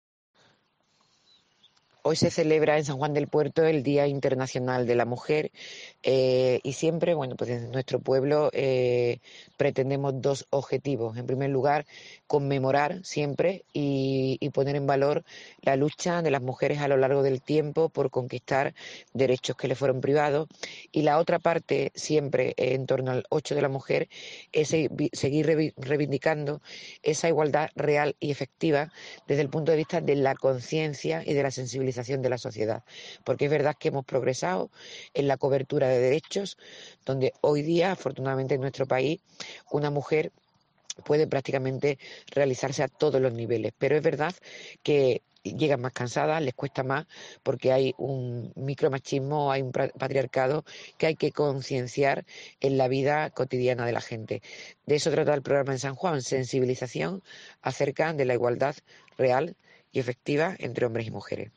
Rocío Cárdenas, alcaldesa de San Juan del Puerto